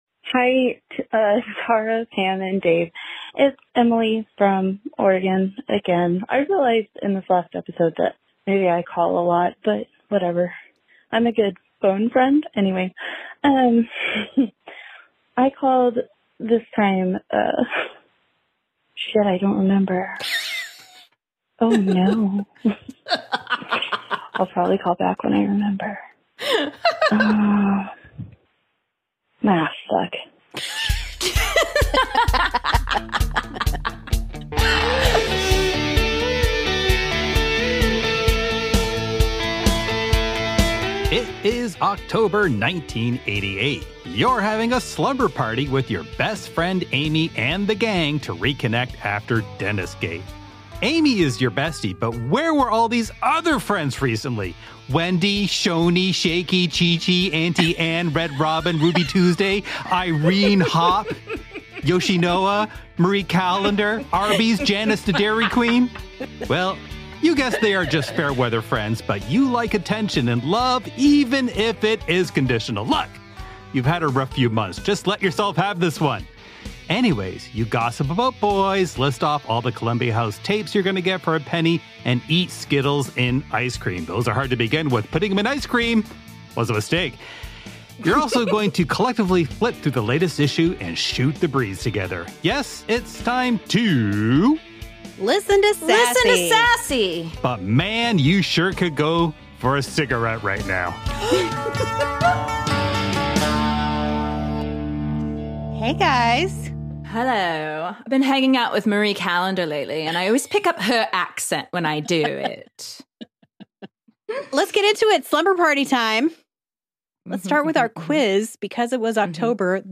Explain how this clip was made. Then we play some of your most recent calls: what happened to someone who did sew bells on an outfit; how effective cortisone shots are on cystic acne; which pens are actually superior; and what is a Pam filter? We also share some of your most recent podcast reviews.